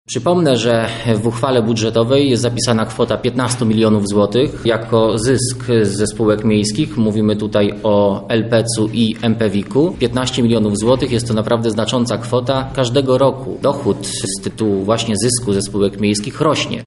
-To nic innego, jak łatanie budżetu – mówi Sylwester Tułajew, przewodniczący PiS na Lubelszczyźnie